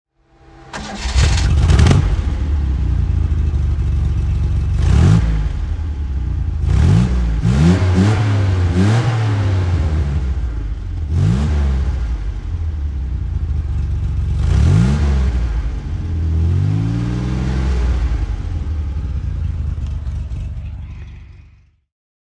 Reliant Scimitar GTE (1971) - Starten und Leerlauf
Reliant_Scimitar_GTE_1971.mp3